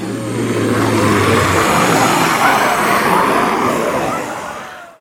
wight1.ogg